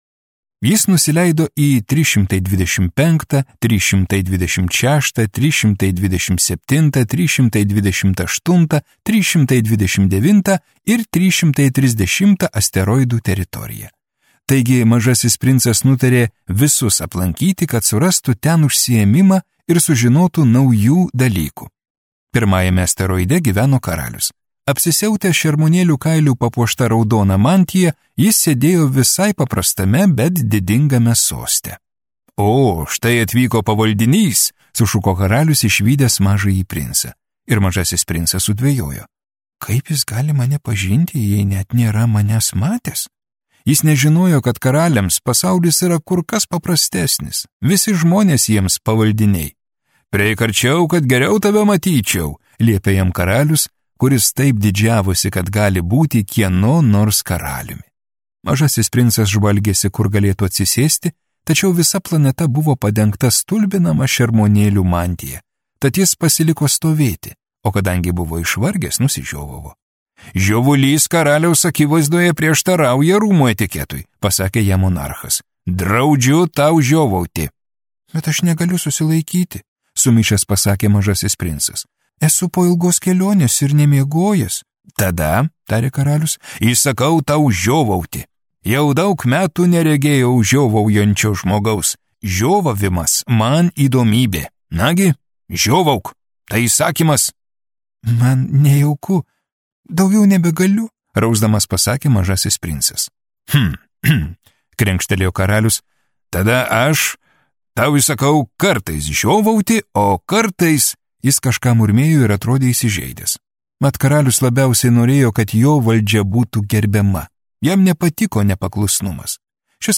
Audio knyga